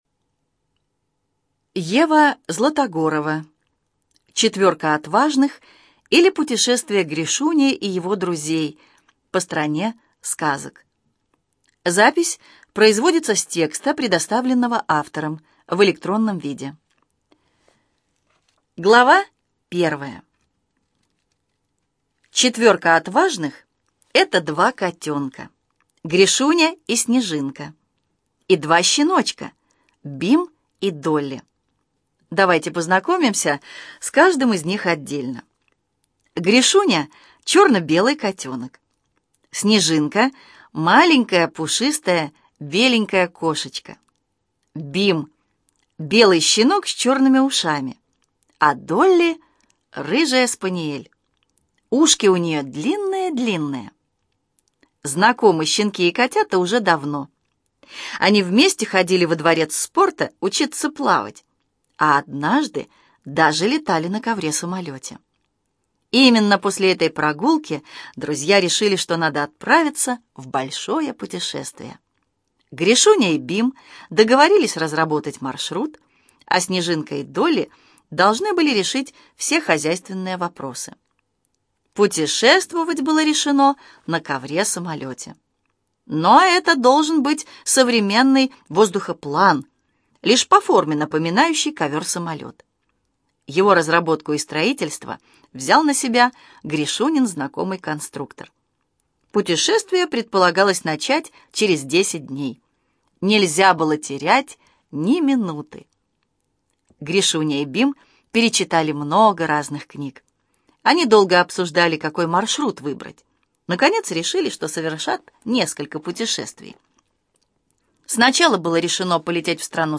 ЖанрДетская литература, Сказки
Студия звукозаписиЛогосвос